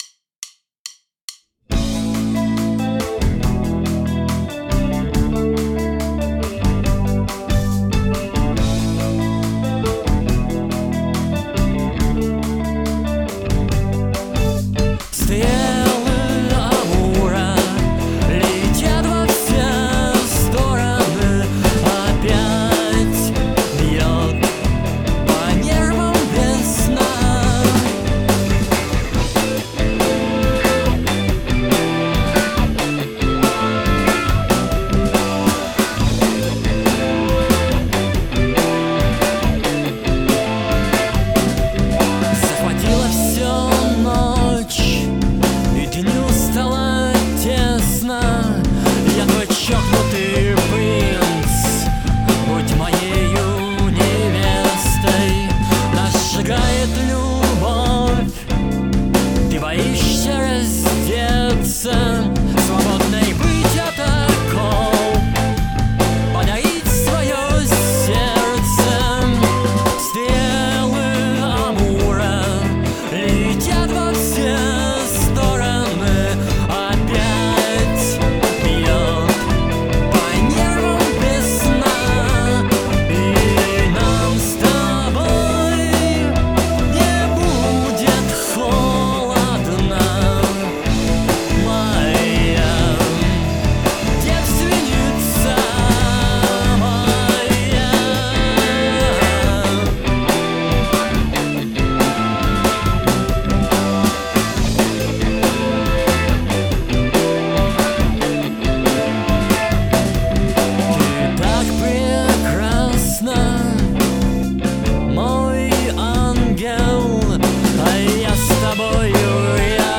группа / Москва / рок / инди
вокал, гитара
барабаны